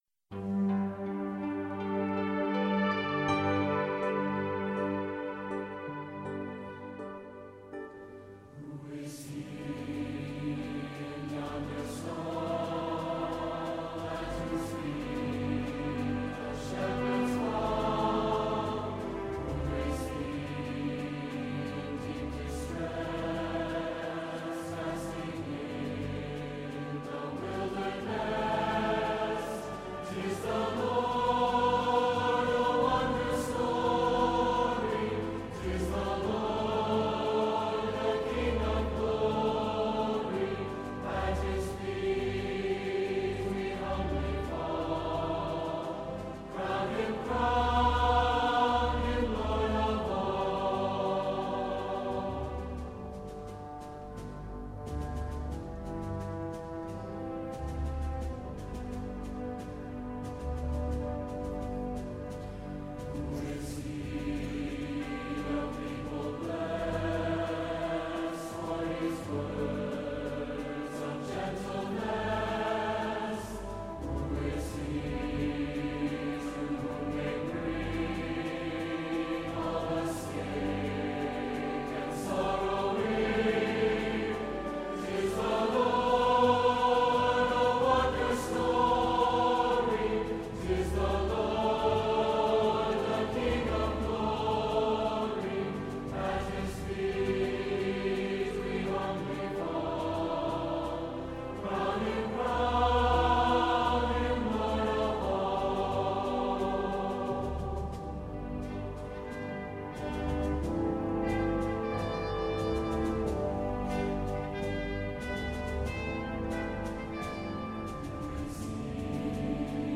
Who-is-He-in-Yonder-Stall-MBI-Choirs-n-Symphonic-Band-1990s2.mp3